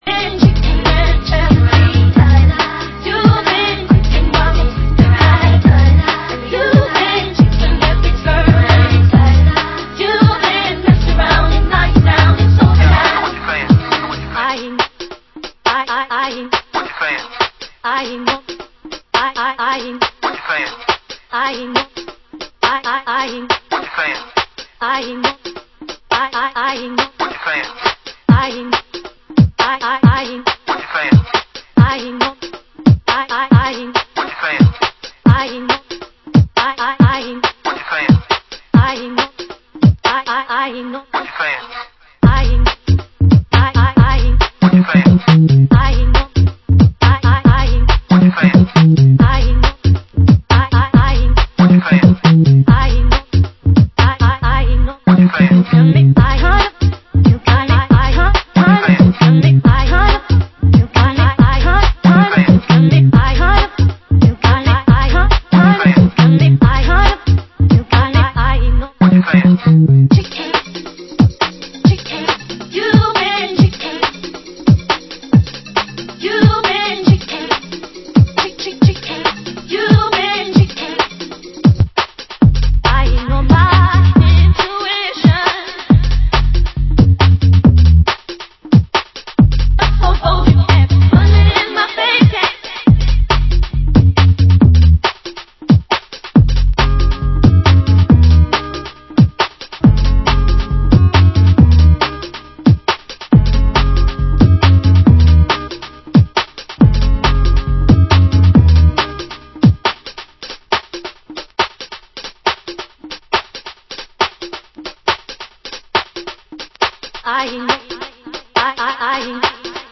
Genre UK Garage